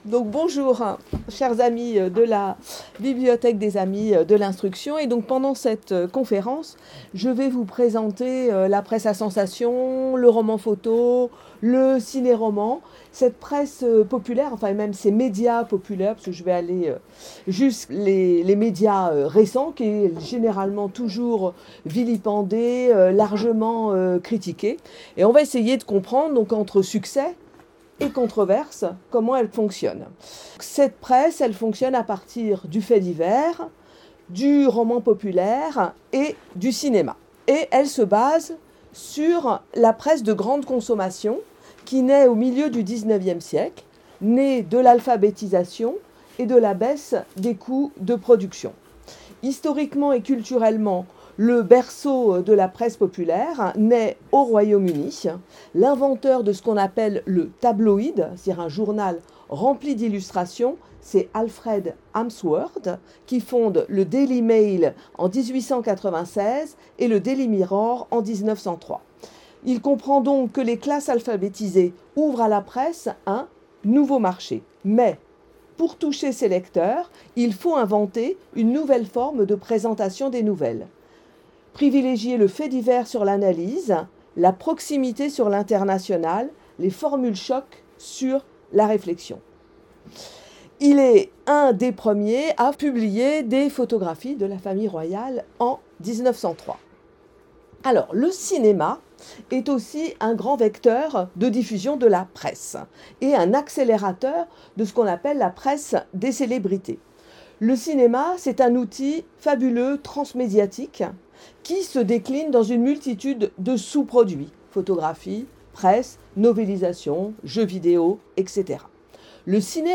Conférence du 10 janvier 2026